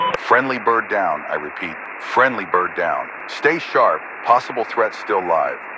Radio-commandFriendlyDown2.ogg